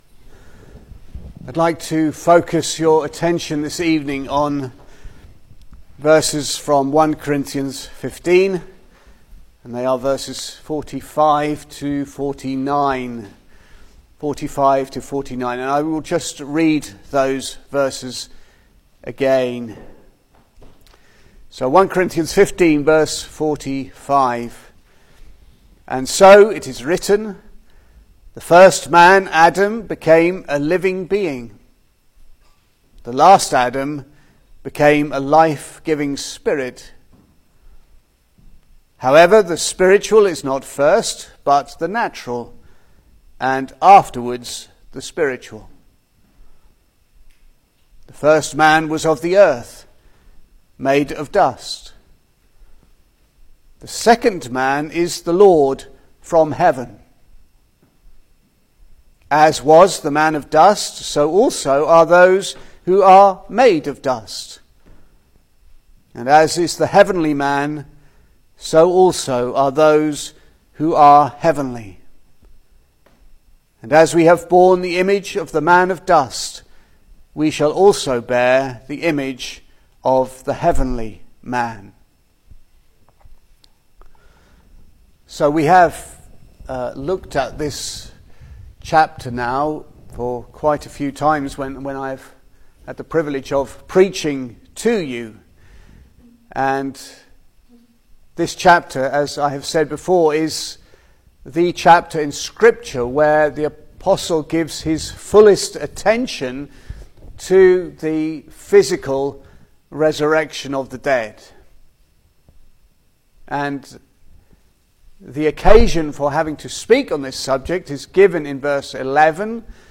Service Type: Sunday Evening
Single Sermons